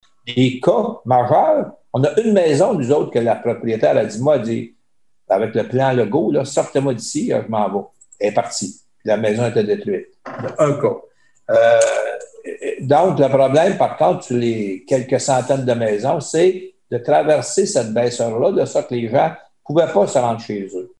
Écouter le maire de Bécancour, Jean-Guy Dubois: